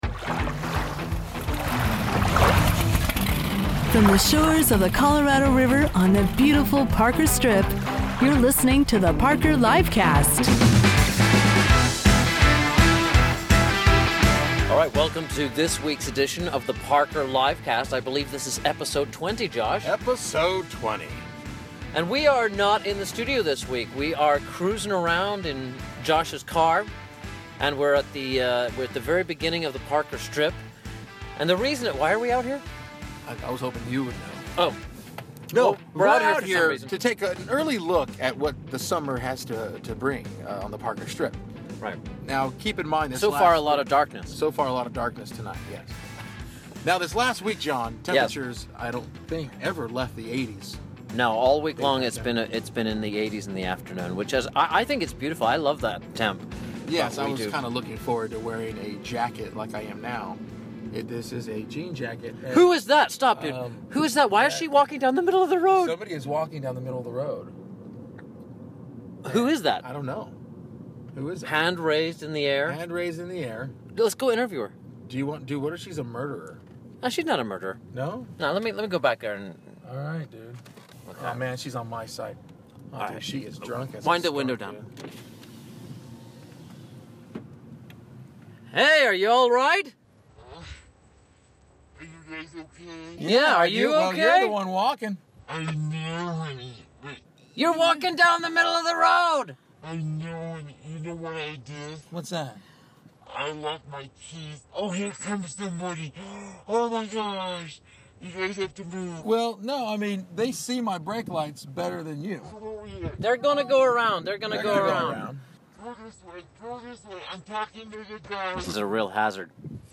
This drive takes them north on the Arizona side, over Parker Dam, south with the river on the California side and across the new road bridge from Earp to the town of Parker.
(name censored and voice altered)